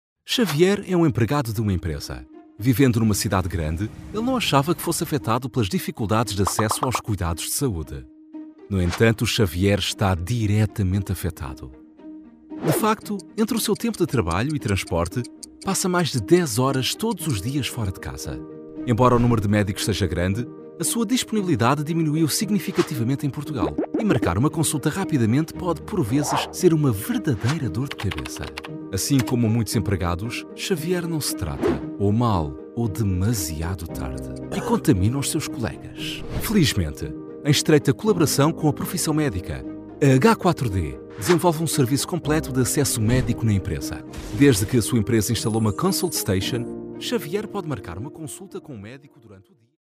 Unternehmensvideos
Vicoustic-Audiokabine, Neumann TLM103-Mikrofon, Neve 1073SPX-Vorverstärker, Apogee Duet 2-Schnittstelle, Highspeed-Kabelinternet.
BaritonBassTiefNiedrigSehr niedrig
FreundlichWarmRuhigFesselndVertrauenswürdig